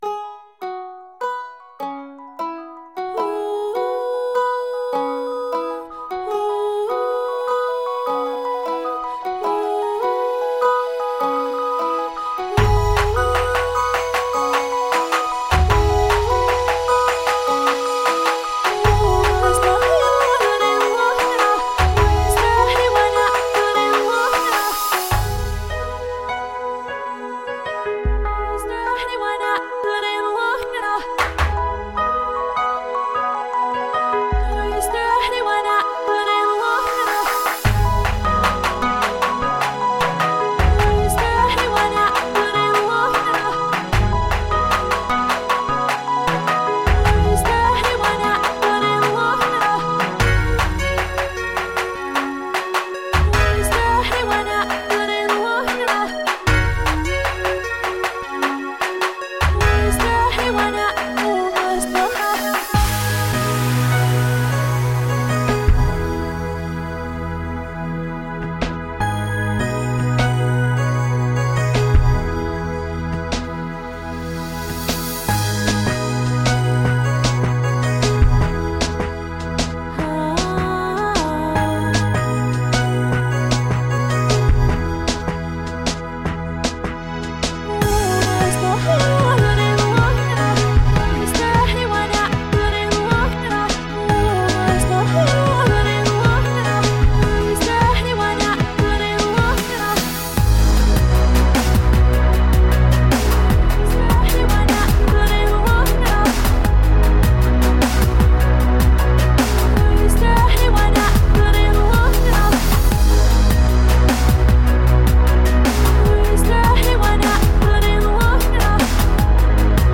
Indie , Pop